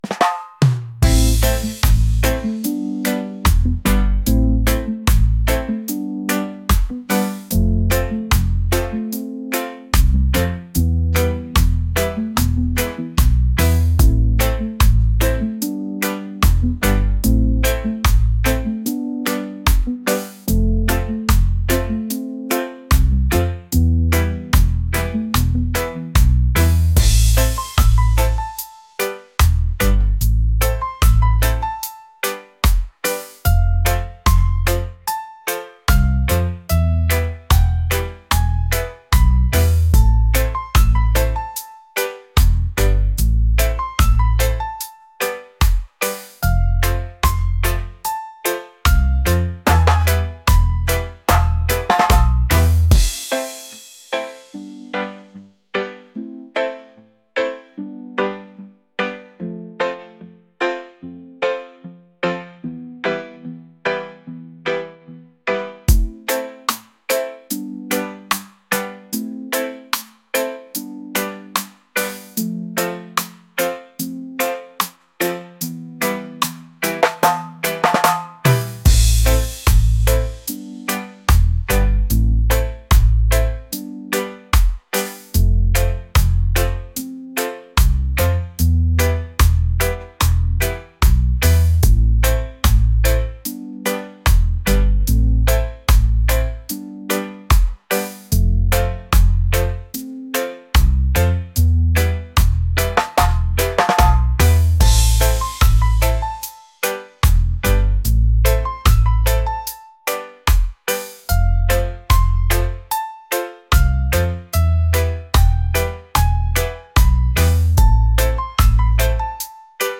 reggae | romantic | laid-back